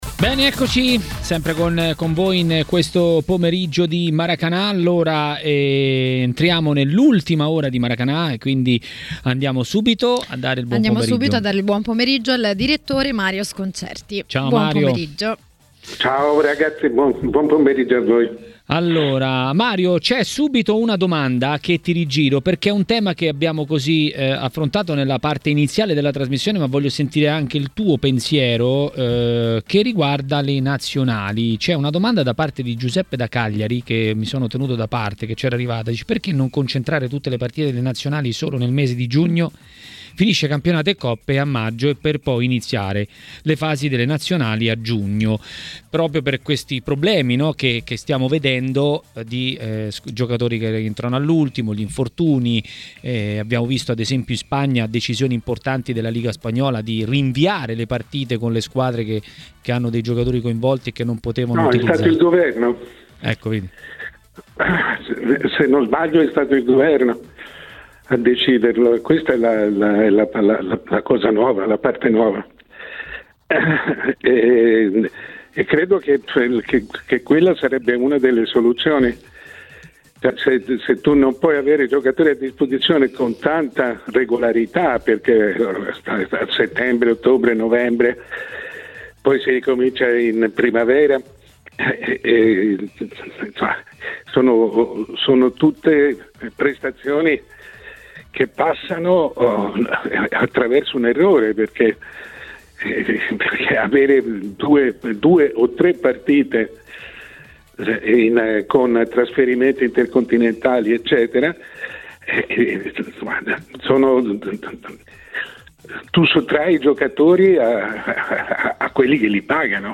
A TMW Radio, durante Maracanà, è intervenuto il direttore Mario Sconcerti.